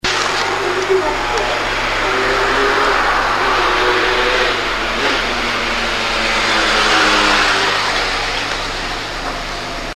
Звуки, записанные в Греции
типичный звук на улице, от которого не хотелось отвыкать (78Kb)